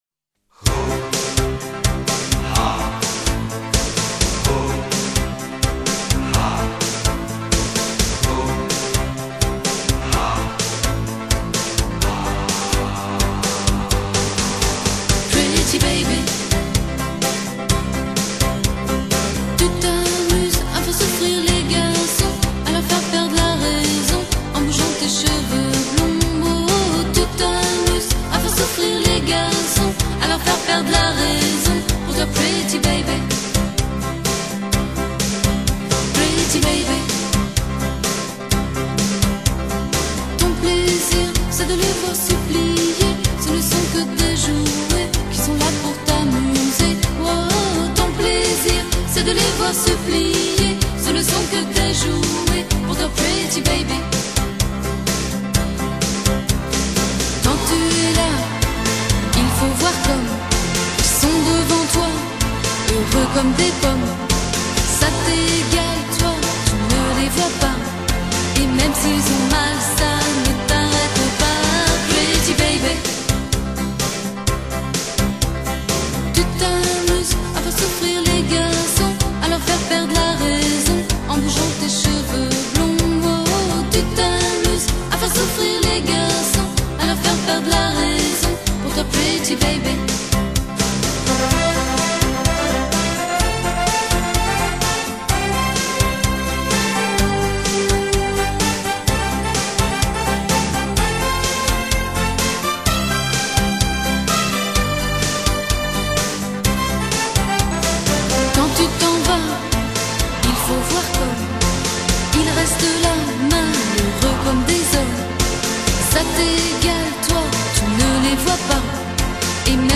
청아한 목소리